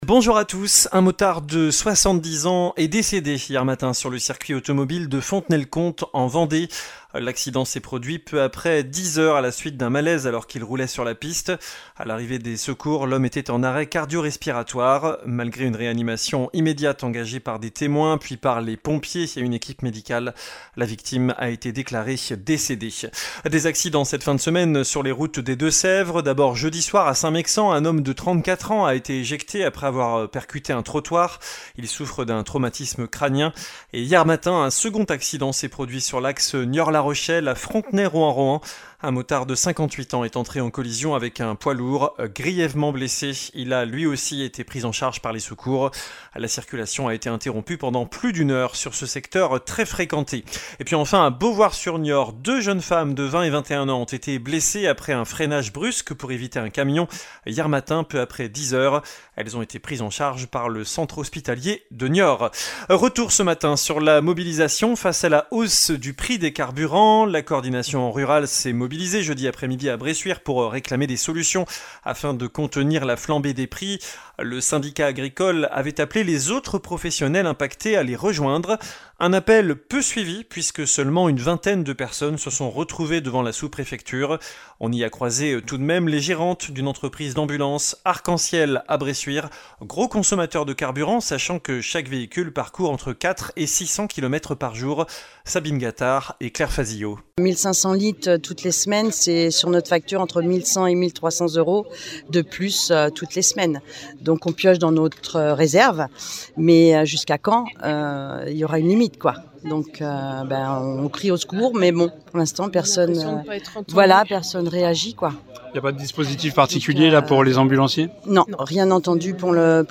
infos Deux-Sèvres